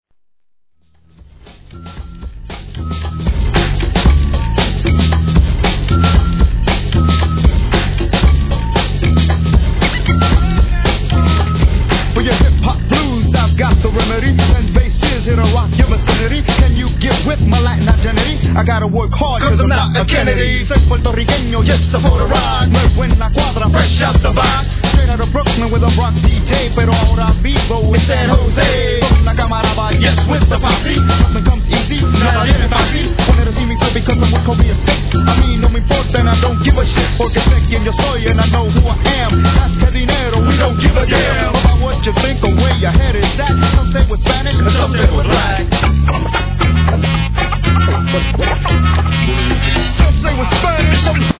1. HIP HOP/R&B
センスあるネタ使いのDOPEトラックで人気の'90sマイナー盤!!